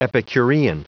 Prononciation du mot epicurean en anglais (fichier audio)
Prononciation du mot : epicurean